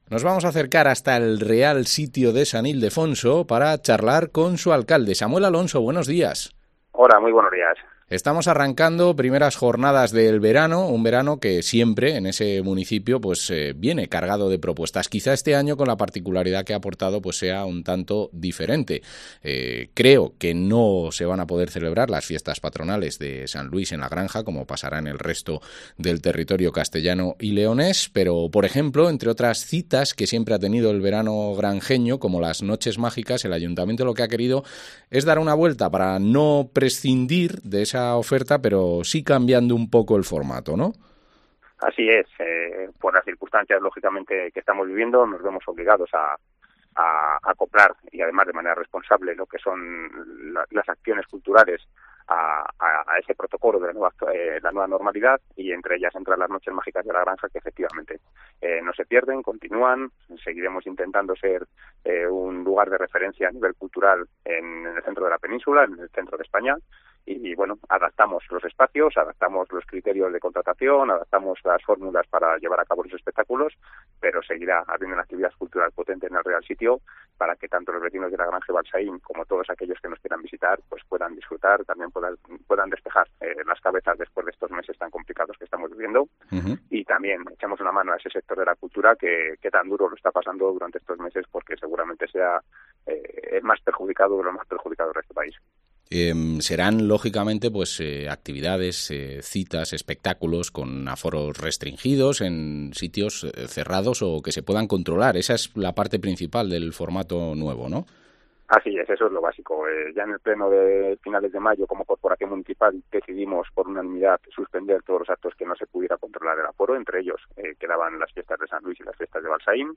Entrevista al alcalde del Real Sitio de San Ildefonso, Samuel Alonso